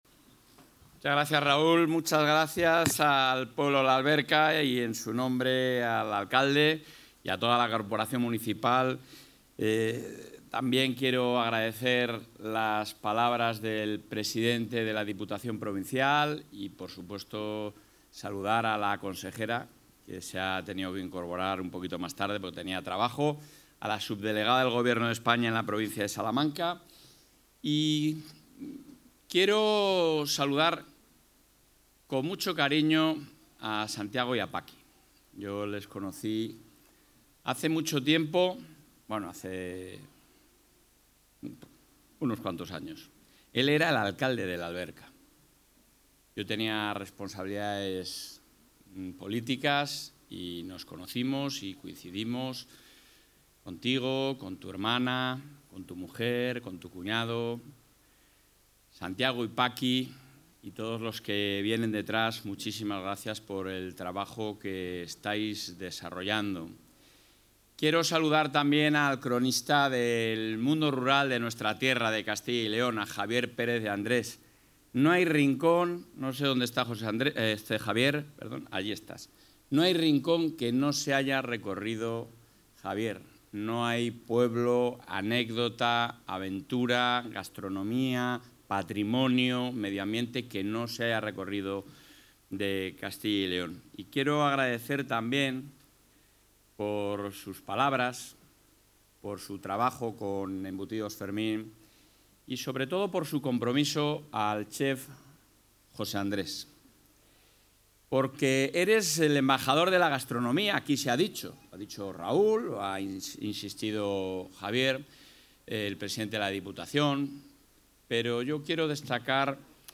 Intervención del presidente de la Junta.
El presidente de la Junta de Castilla y León, Alfonso Fernández Mañueco ha asistido hoy, en el municipio salmantino de La Alberca, al acto conmemorativo del 20º aniversario de la internacionalización de la empresa Embutidos Fermín, donde ha anunciado la publicación mañana de la resolución del Instituto Tecnológico Agrario de Castilla y León (ITACyL) que permitirá a las empresas agroalimentarias de la Comunidad participar sin coste en los principales salones y ferias del sector durante el año 2026.